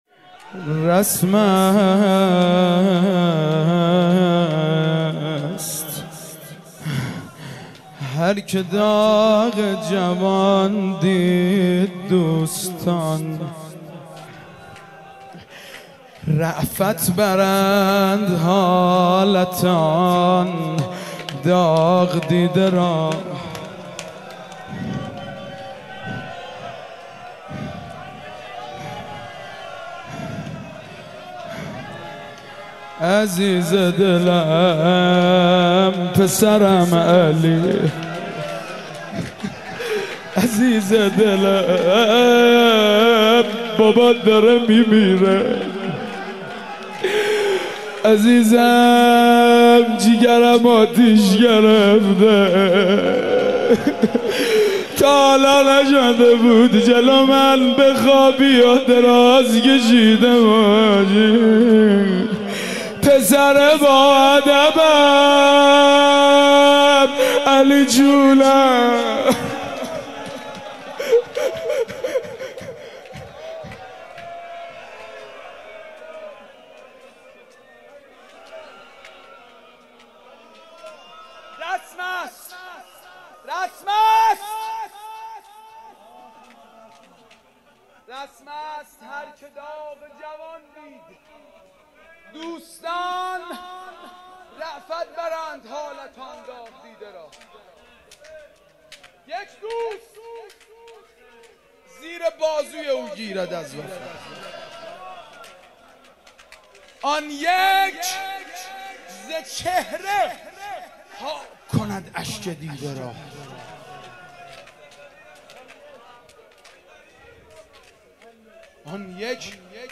14-Rozeh-3.mp3